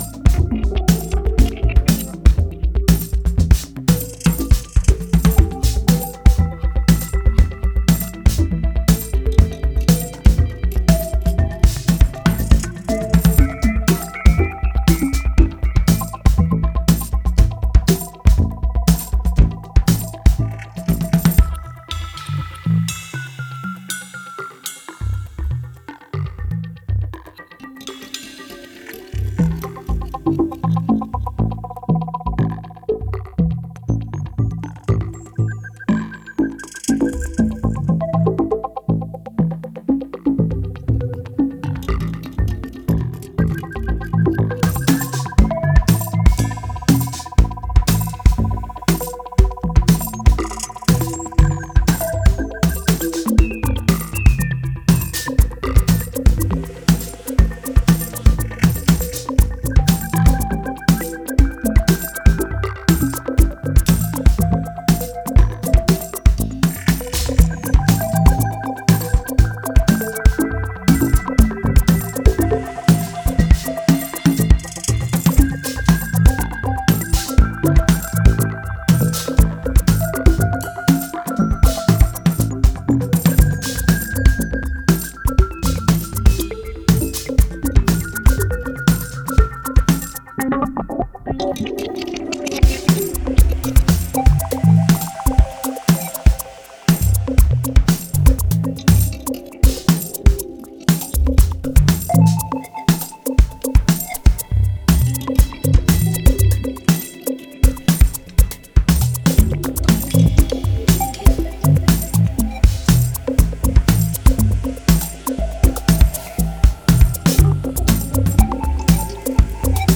ミニマル・ハウス的とも取れる揺らぎから音数は少なくとも豊かなテクスチャーを感じる